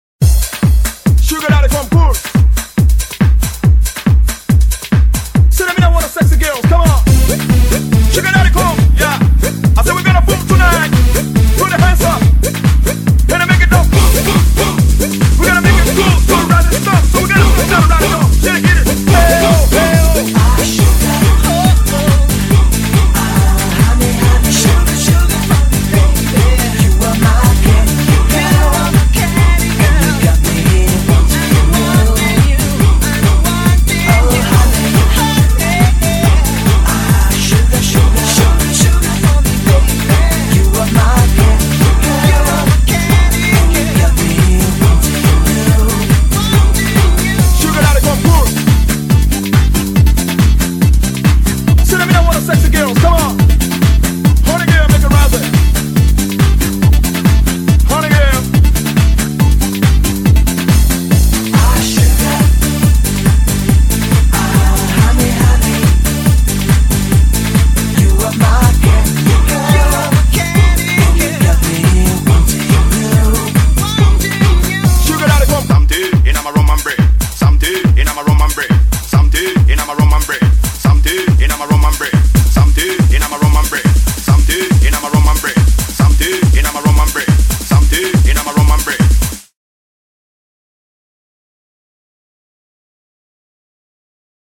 BPM140--1
Audio QualityPerfect (High Quality)